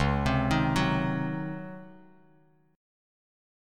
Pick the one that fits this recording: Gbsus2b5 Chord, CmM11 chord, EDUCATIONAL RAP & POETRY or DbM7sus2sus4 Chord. DbM7sus2sus4 Chord